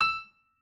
pianoadrib1_31.ogg